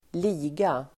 Uttal: [²l'i:ga]